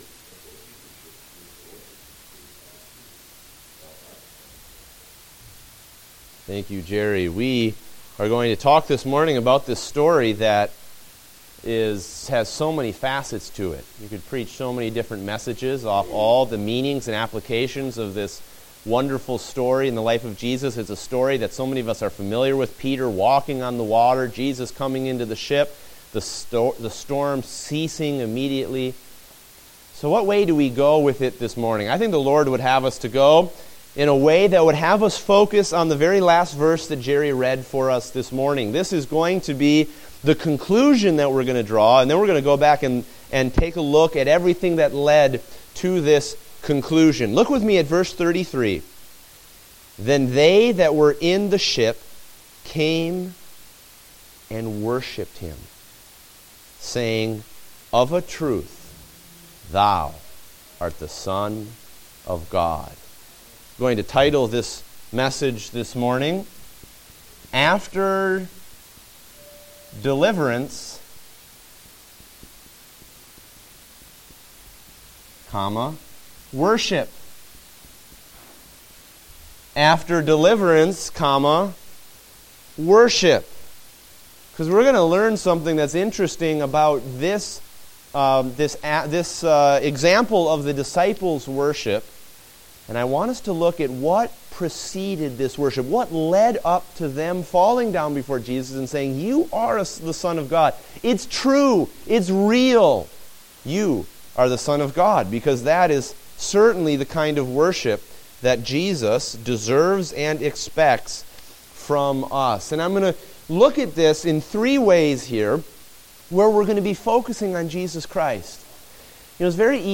Date: December 13, 2015 (Adult Sunday School)